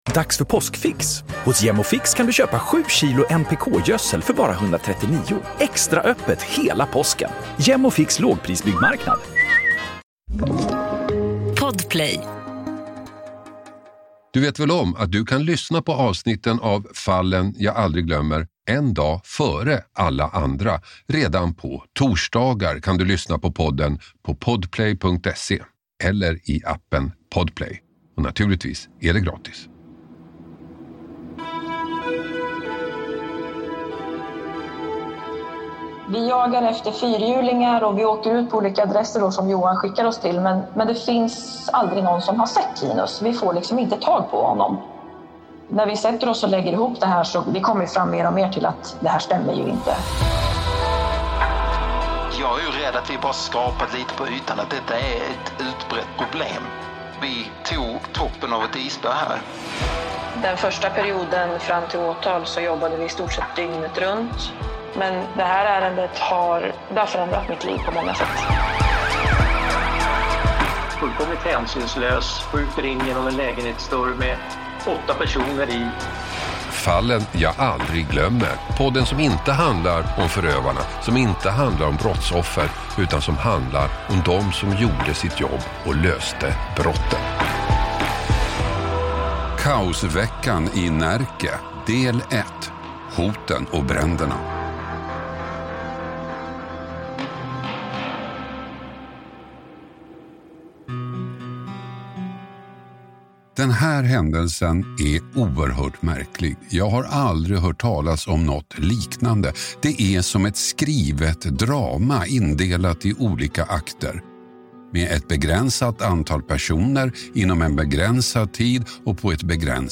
Hasse Aro intervjuar utredare